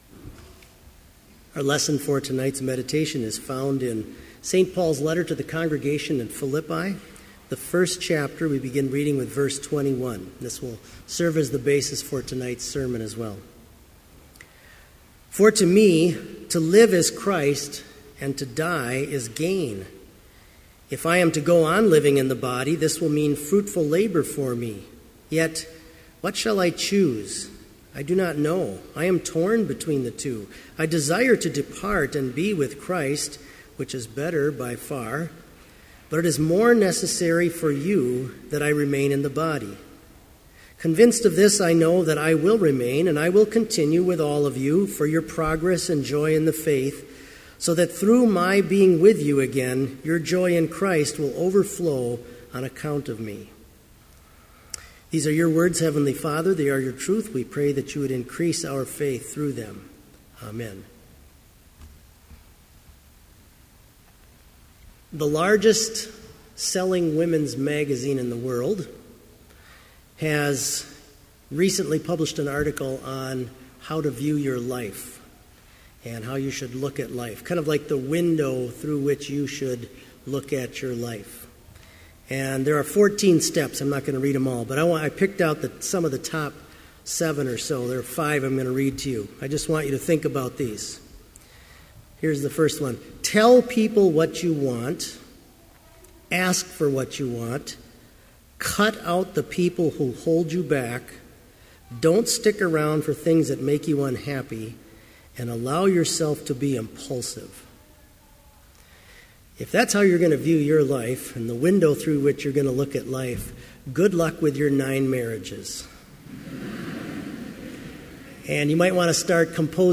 Vespers worship service in BLC's Trinity Chapel
Sermon audio for Commencement Vespers - May 14, 2015